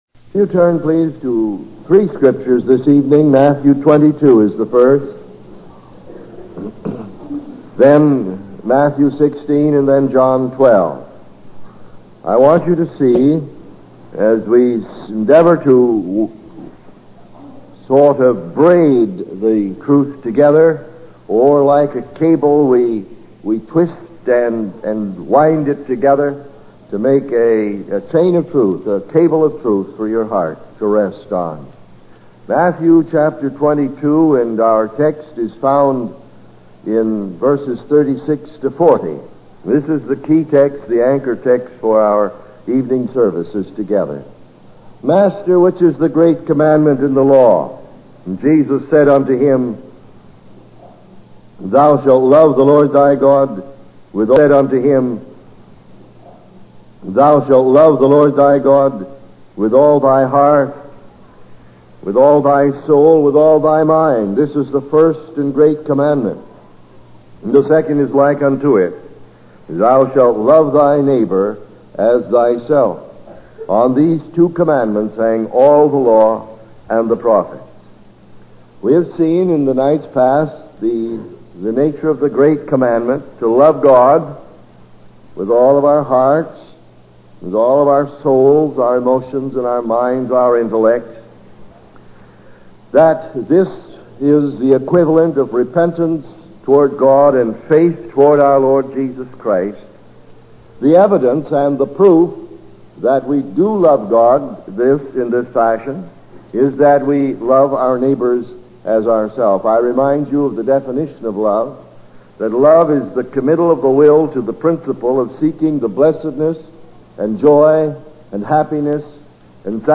In this sermon, the speaker discusses the concept of negative feedback guidance in missile technology as an analogy for following Jesus. He emphasizes the importance of committing oneself to follow and obey Jesus as Lord and Savior.